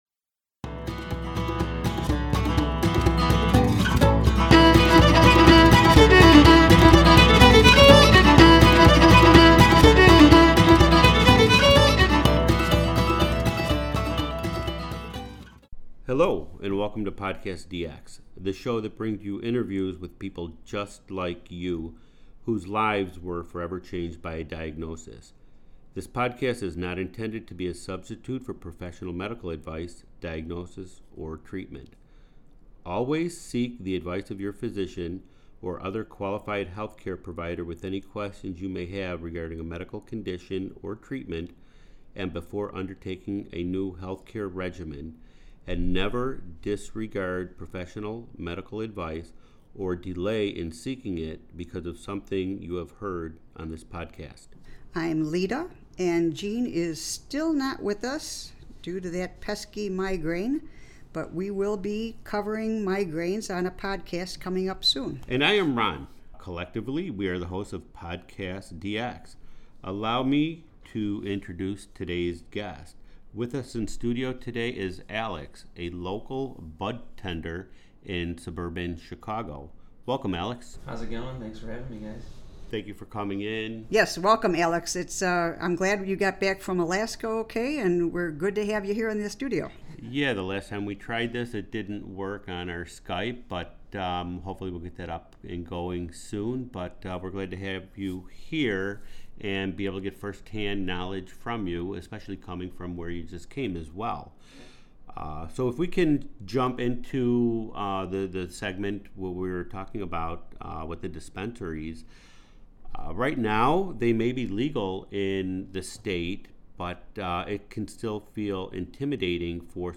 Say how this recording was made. Allow me to introduce today's guest with us in studio.